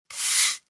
clean floor.mp3